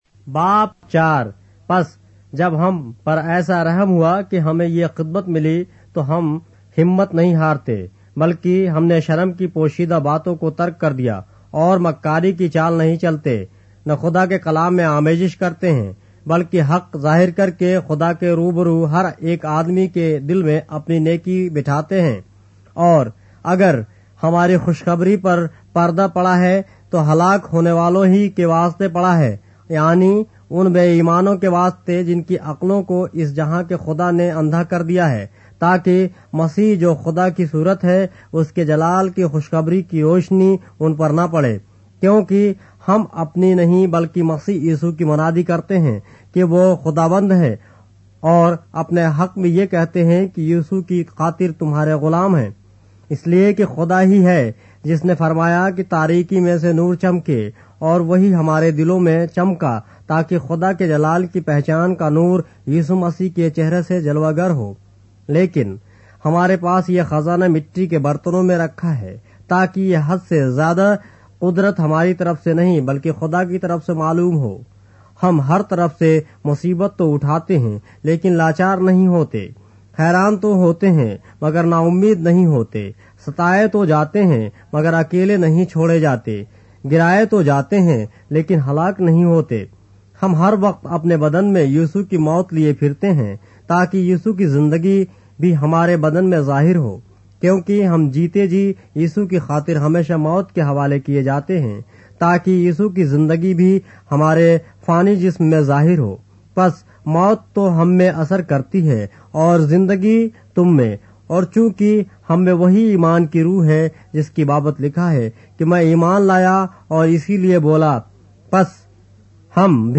اردو بائبل کے باب - آڈیو روایت کے ساتھ - 2 Corinthians, chapter 4 of the Holy Bible in Urdu